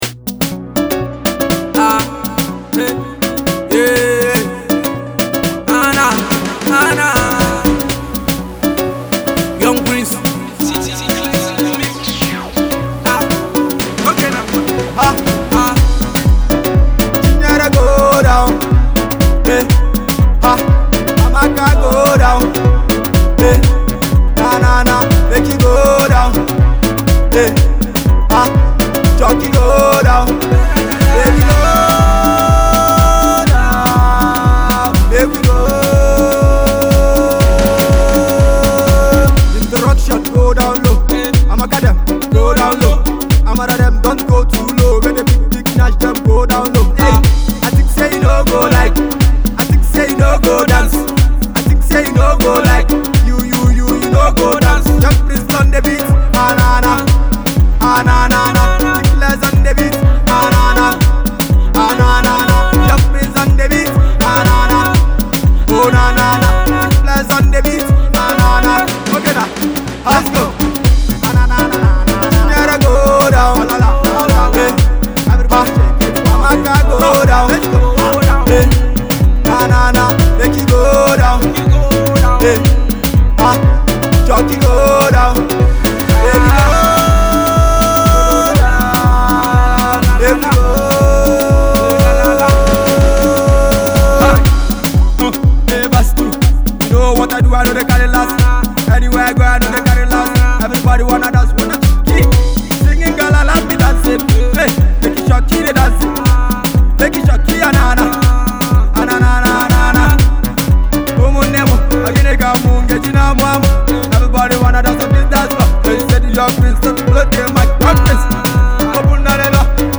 His genre is Dancehall and Pop